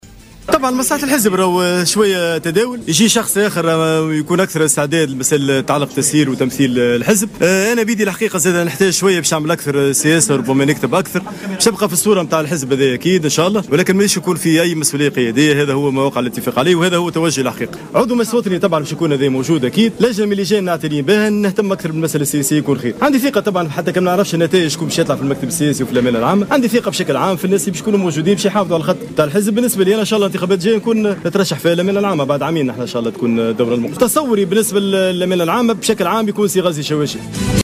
انطلقت اليوم الجمعة 25 مارس 2016 فعاليات المؤتمر الأول لحزب التيار الديمقراطي لتتواصل إلى غاية يوم الأحد 27 مارس 2016 .
وقال الأمين العام للحزب، محمد عبو في تصريح لمراسل "الجوهرة أف أم" إنه قرّر عدم الترشح للأمانة العامة للحزب وسيترك المجال للتداول على المسؤوليات.